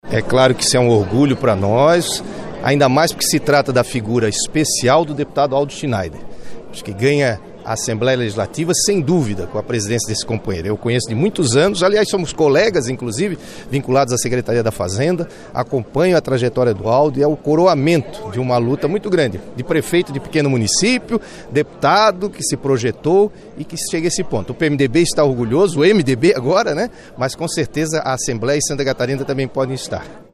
Confira a análise de personalidades presentes na Assembleia Legislativa:
- Paulo Afonso Vieira (PMDB) – ex-governador de SC;